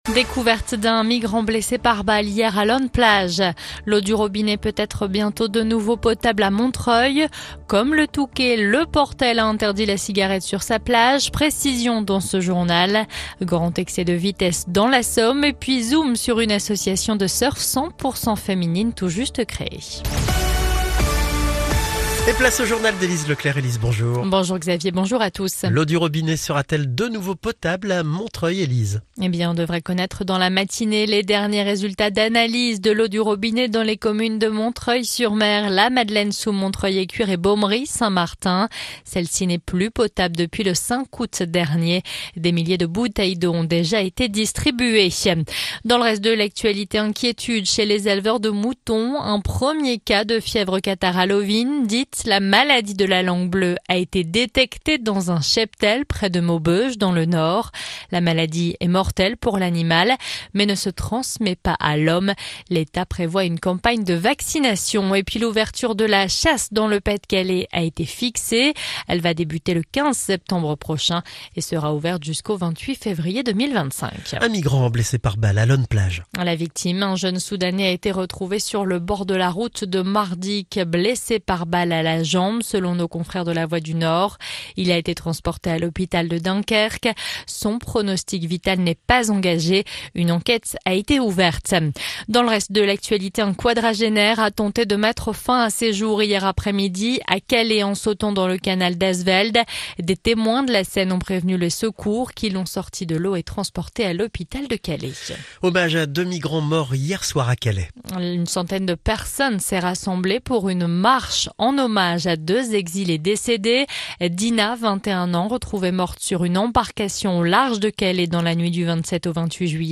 Le journal du jeudi 8 août
(journal de 9h)